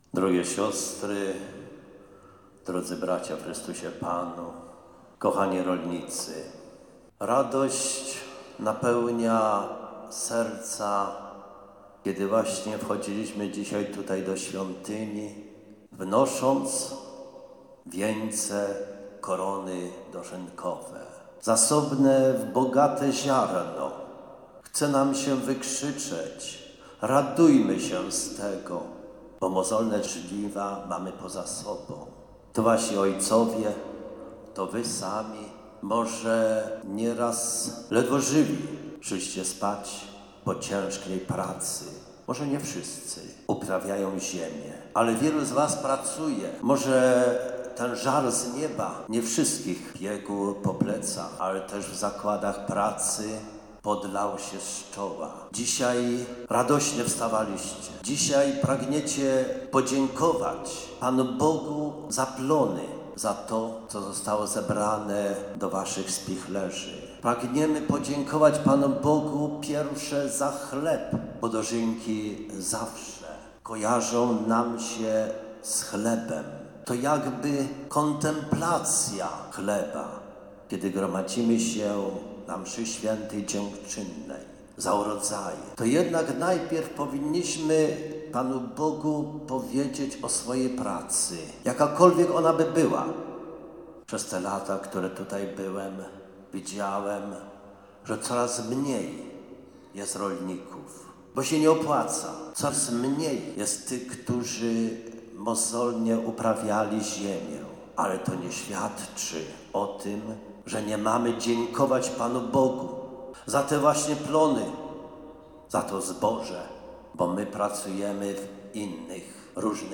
msza